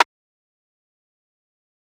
Rimshot [2].wav